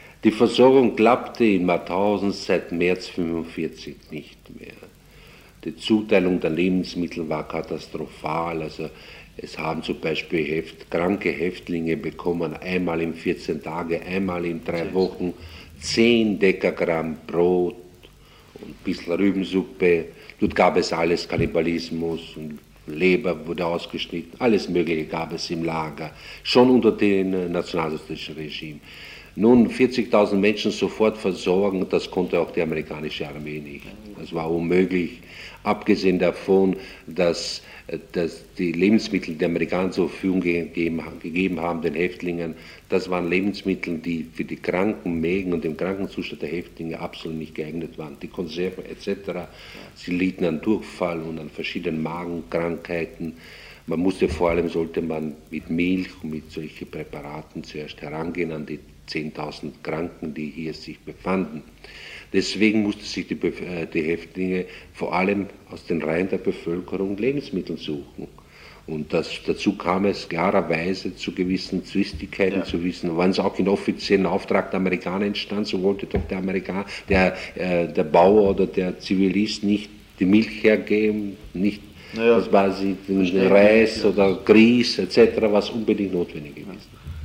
Ausschnitt aus einem Interview